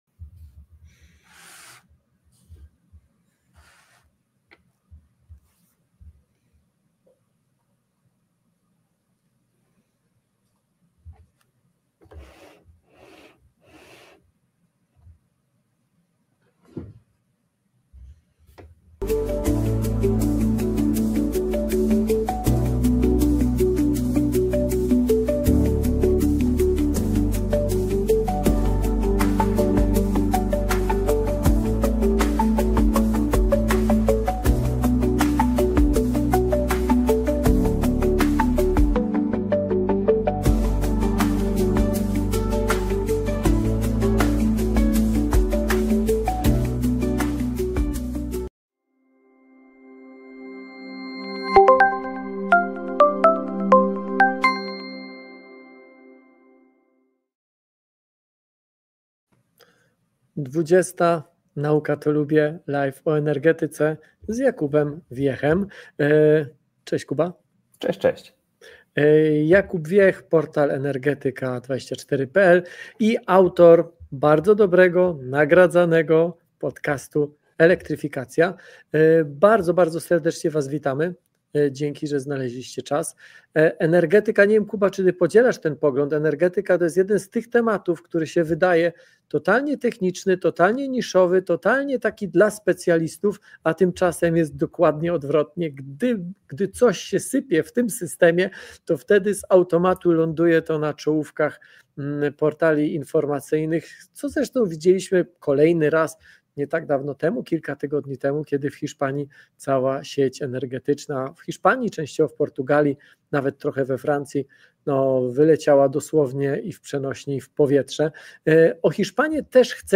Prowadzący: dr Tomasz Rożek – popularyzator nauki, fizyk